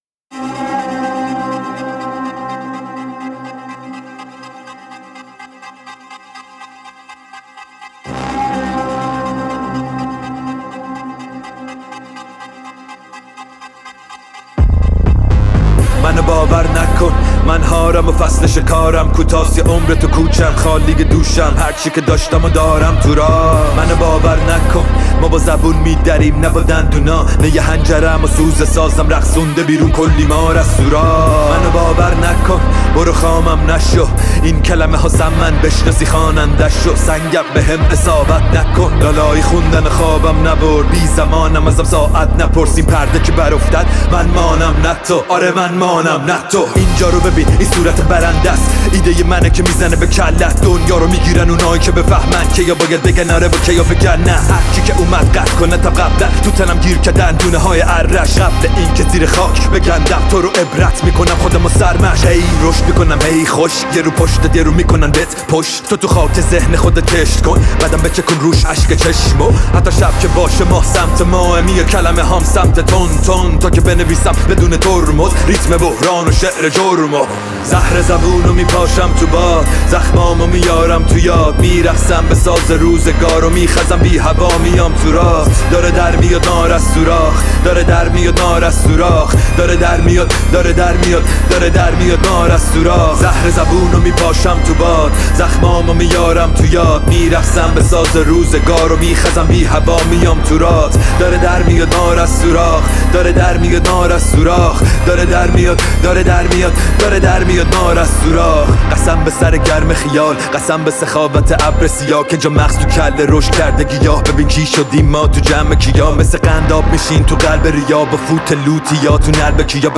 ترانه اعتراضیِ رپ